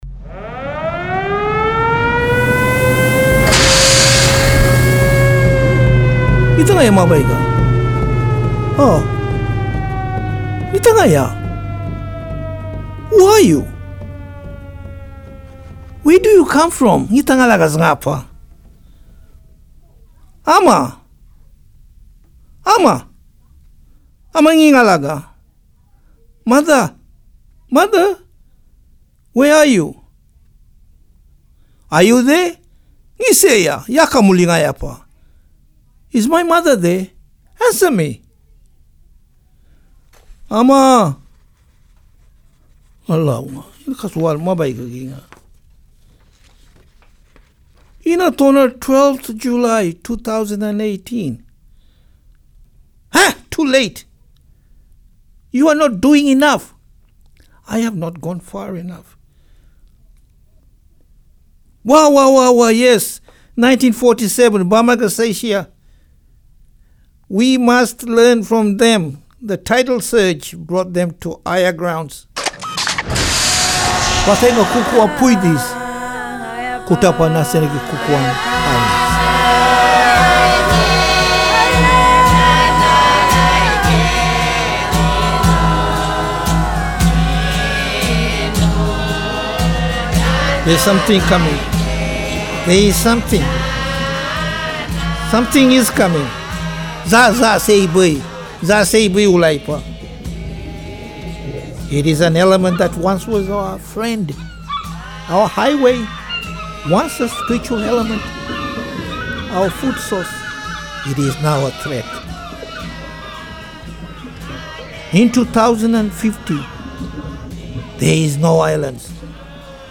Woer Wayepa, audio visual performance 2018.
Commissioned for the Cairns Indigenous Art Fair 2018.